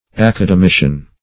Academician \Ac`a*de*mi"cian\ (#; 277), n. [F. acad['e]micien.